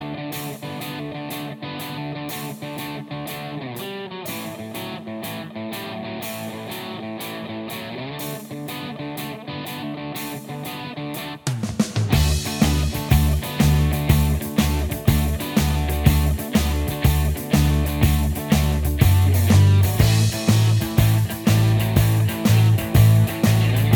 Minus Guitars Rock 4:09 Buy £1.50